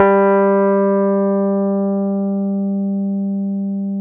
RhodesG3.wav